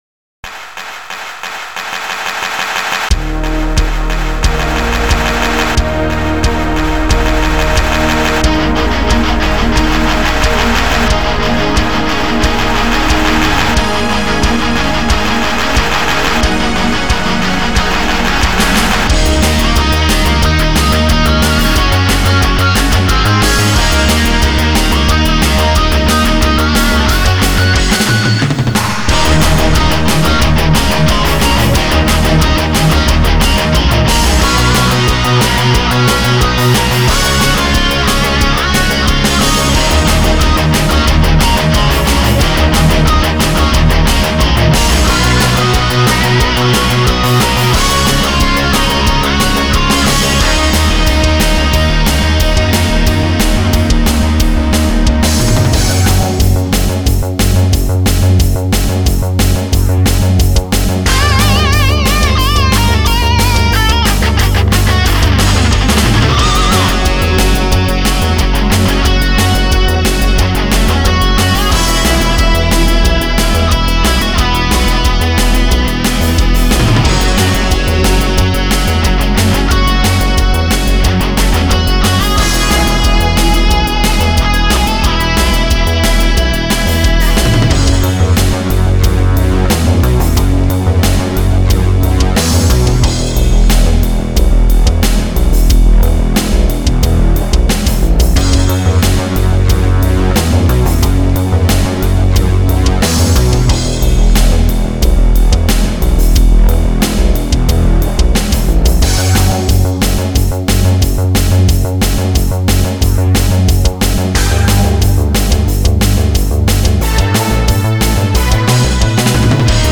metal remix